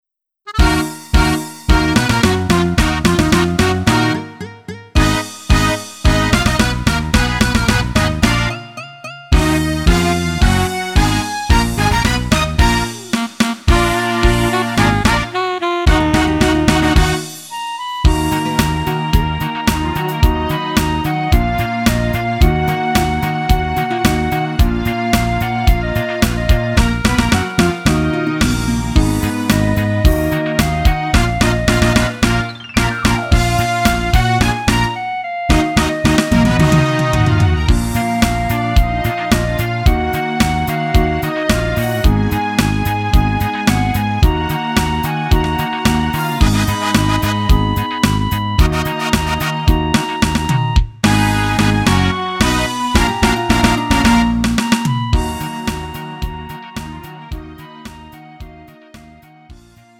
음정 원키 2:16
장르 가요 구분 Lite MR